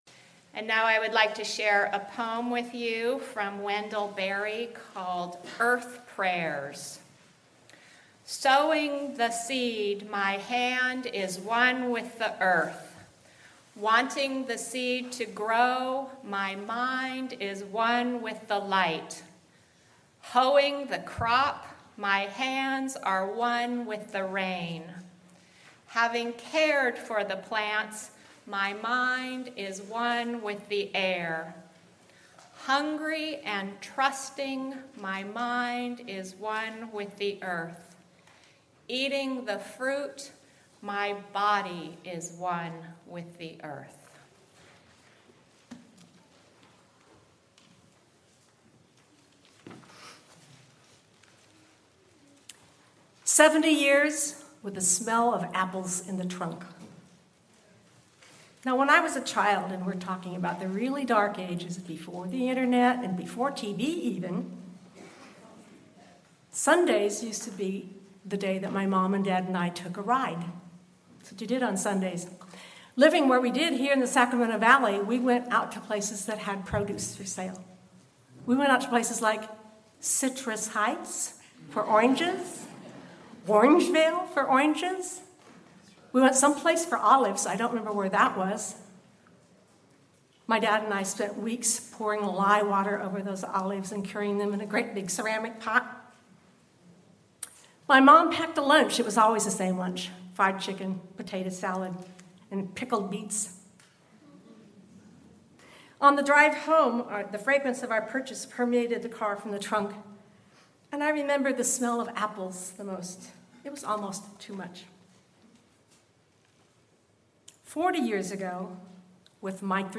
Come celebrate the bounty of the summer harvest at this service for all ages. We will hear stories, songs and poems about gardening, and celebrate the bounty of the earth with a “tomato communion.”
Sermon-Celebrating-the-Summer-Harvest.mp3